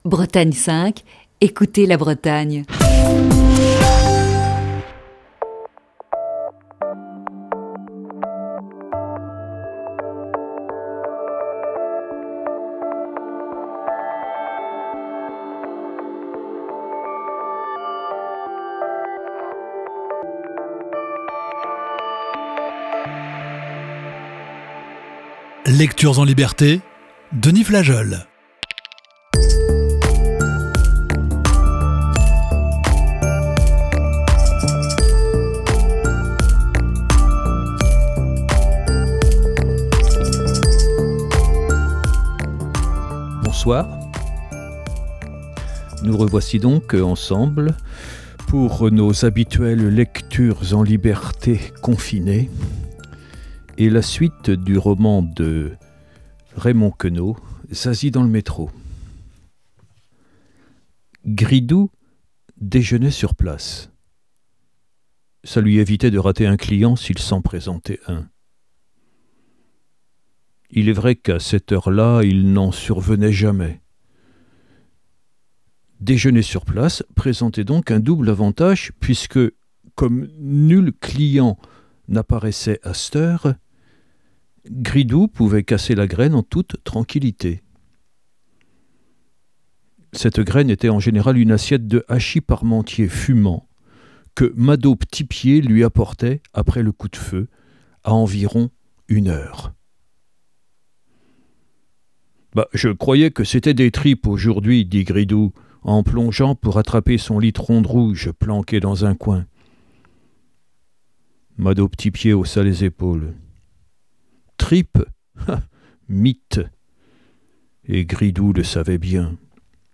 Émission du 3 avril 2020.